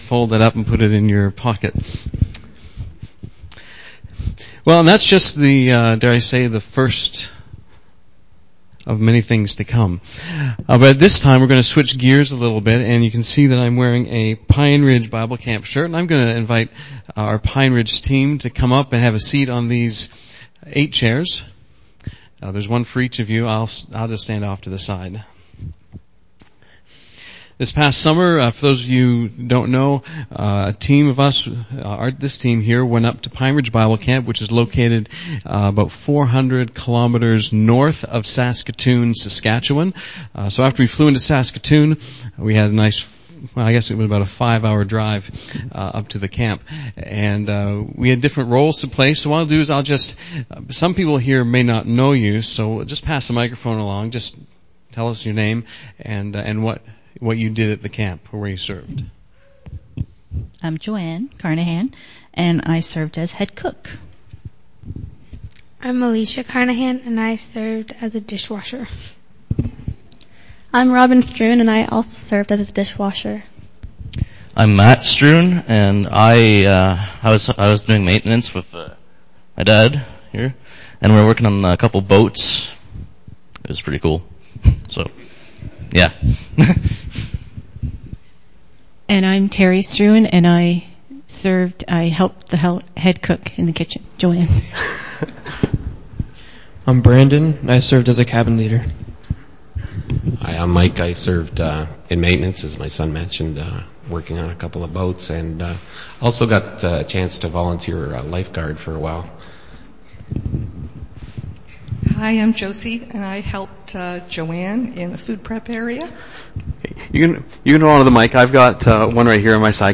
September 8, 2013 Pine Ridge Bible Camp Sunday BACK TO SERMON LIST Preacher: Pine Ridge STM Team Preacher: Pine Ridge STM Team « Get a Life!